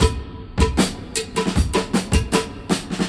• 78 Bpm '00s Breakbeat Sample C Key.wav
Free drum loop sample - kick tuned to the C note. Loudest frequency: 1884Hz
78-bpm-00s-breakbeat-sample-c-key-ekE.wav